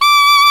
Index of /90_sSampleCDs/Roland LCDP07 Super Sax/SAX_Alto Short/SAX_Pop Alto
SAX C 5 S.wav